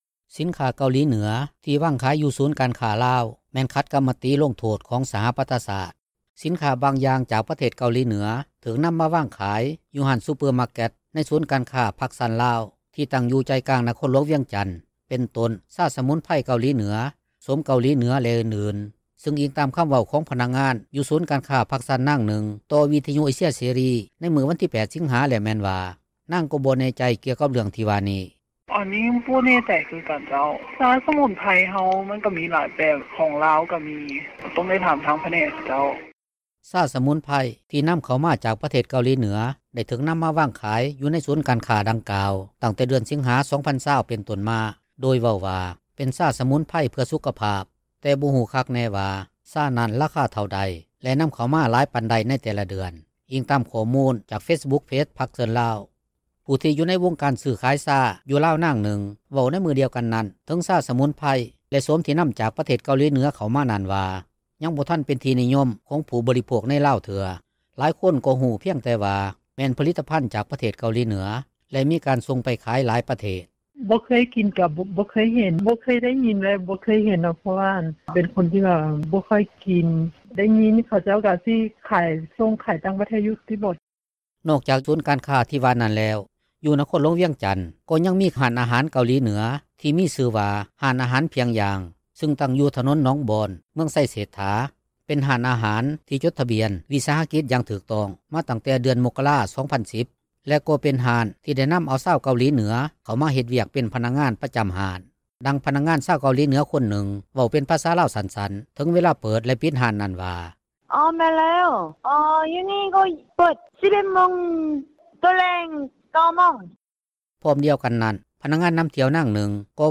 ດັ່ງພະນັກງານ ຊາວເກົາຫລີເໜືອຄົນນຶ່ງ ເວົ້າເປັນພາສາລາວສັ້ນໆ ເຖິງເວລາເປີດ ແລະປິດຮ້ານນັ້ນວ່າ: